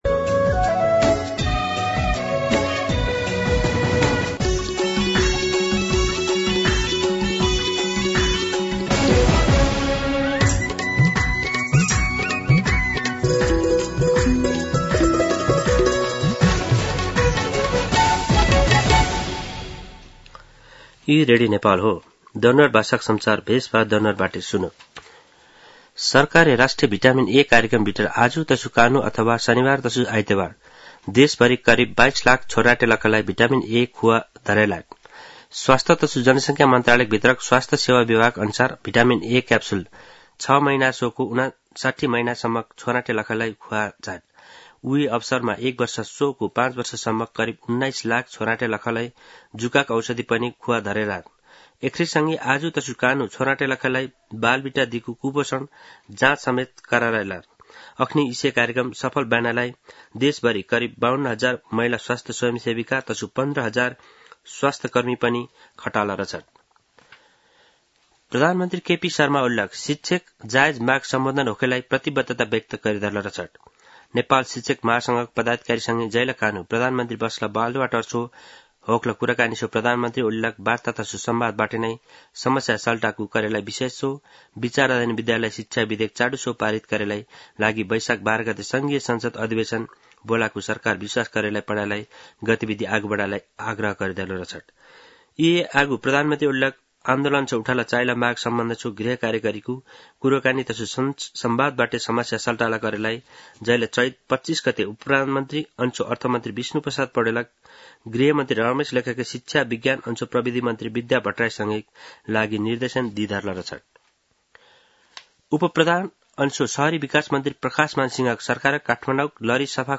दनुवार भाषामा समाचार : ६ वैशाख , २०८२
Danuwar-News-9.mp3